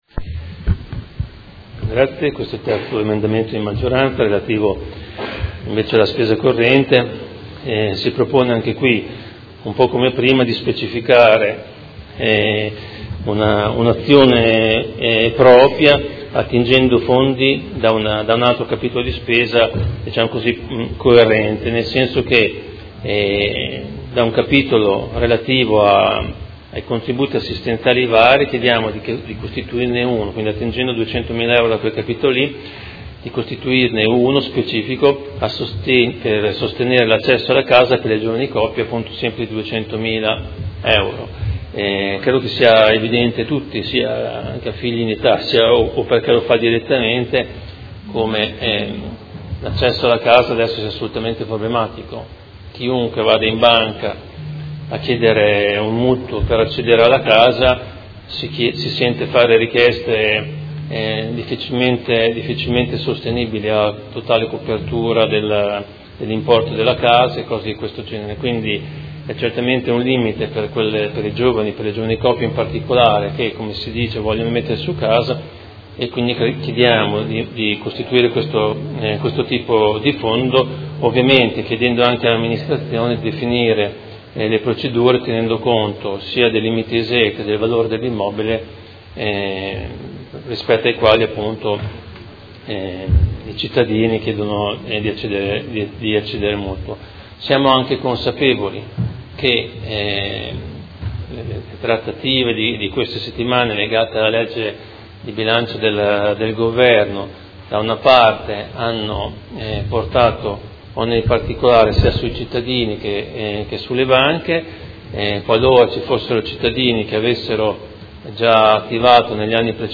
Seduta del 20/12/2018. Presenta emendamento Prot. Gen. 197169